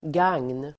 Uttal: [gang:n]